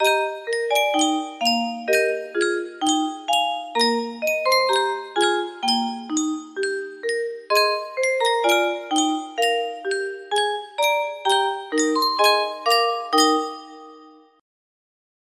Yunsheng Music Box - Norway National Anthem Y458 music box melody
Full range 60